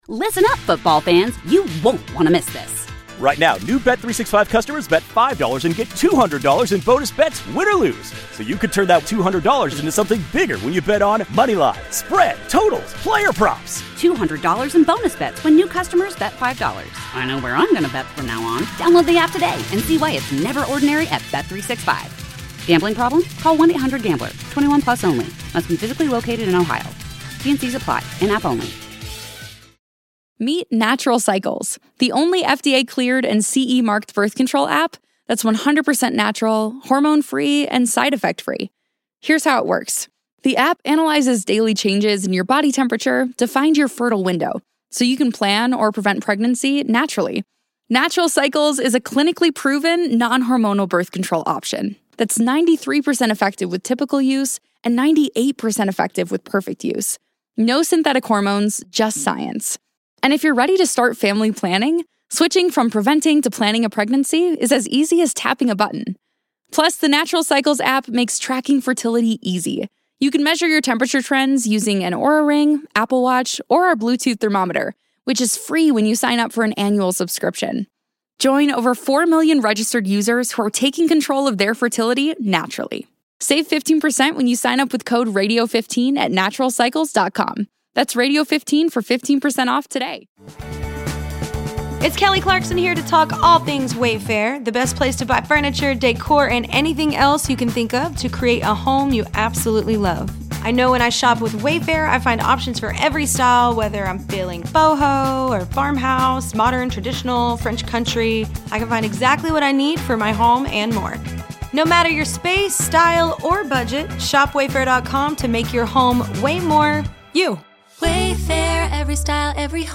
THE EXTRA POINT is designed for full engagement with bills fans as the two hosts open the phones, texts sand tweets to connect with the Bills mafia on everything Bills!